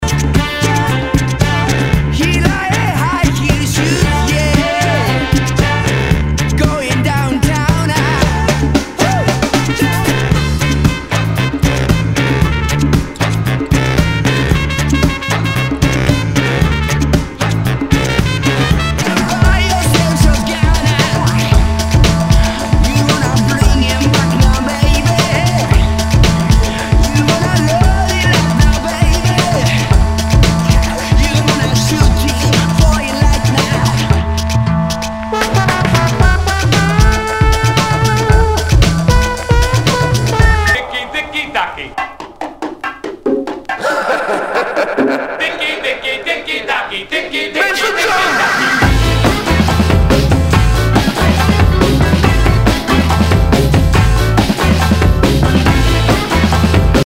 Nu- Jazz/BREAK BEATS
ナイス！ダウンテンポ / ブレイクビーツ！